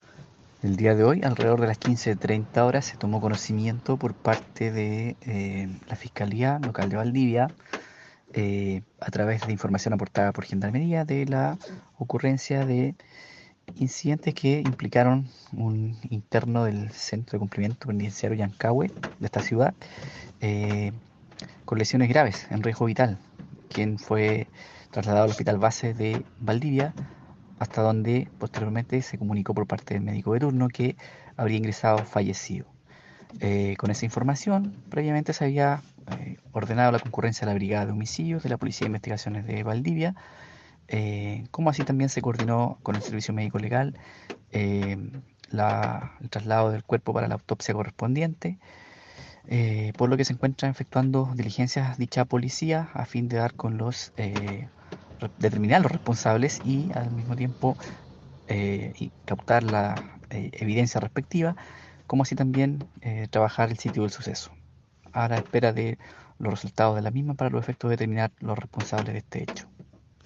El fiscal de turno José Luis Vallejos sobre la investigación que dirige la Fiscalía de Valdivia por un homicidio con arma blanca cometido hoy contra un interno del módulo 42 del Complejo Penitenciario de esta ciudad.